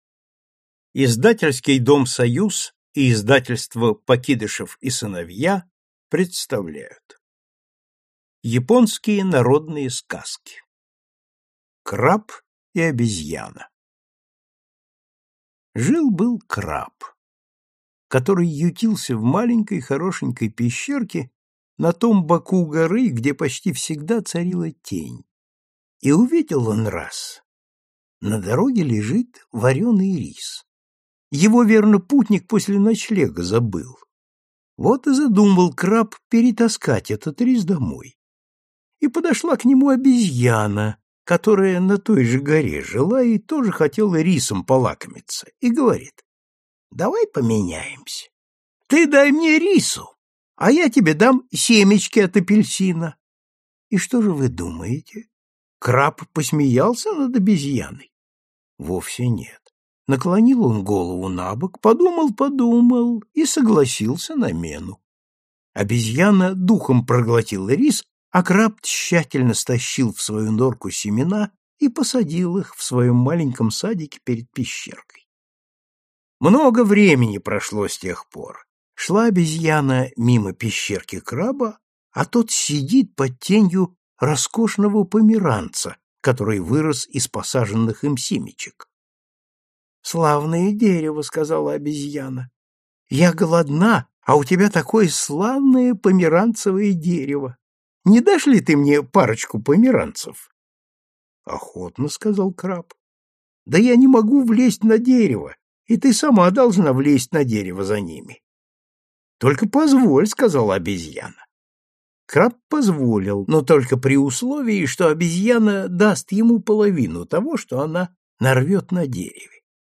Аудиокнига Японские сказки | Библиотека аудиокниг